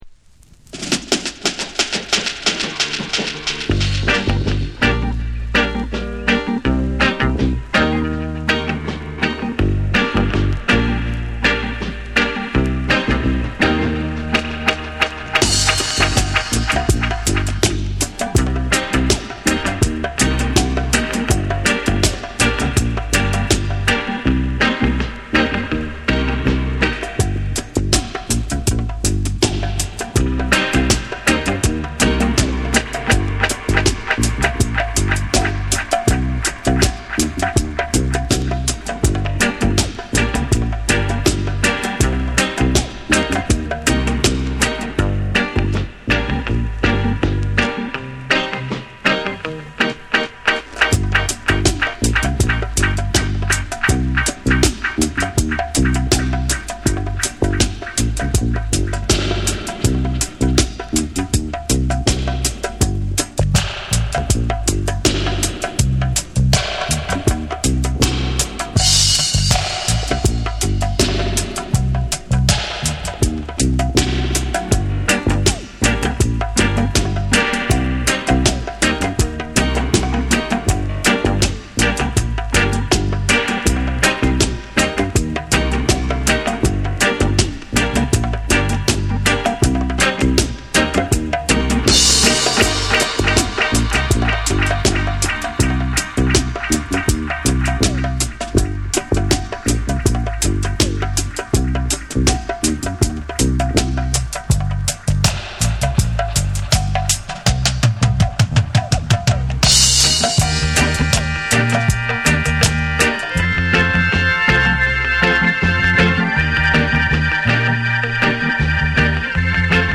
REGGAE & DUB